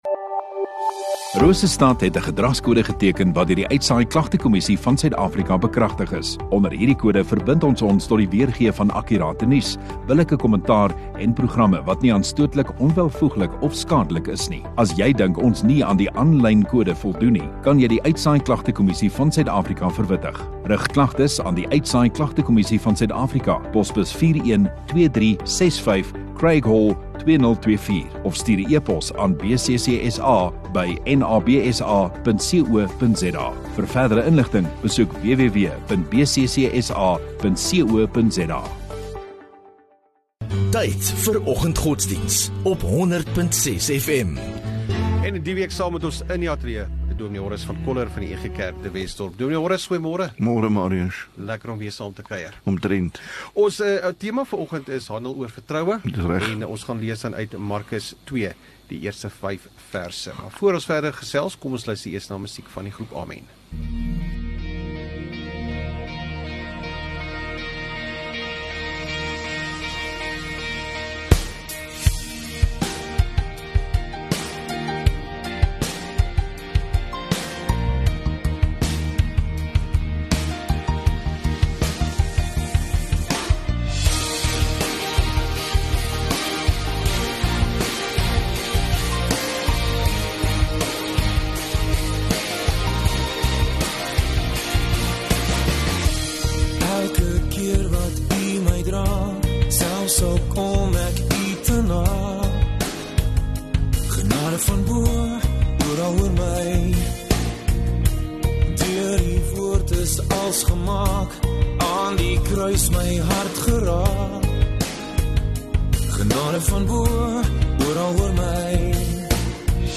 18 Nov Maandag Oggenddiens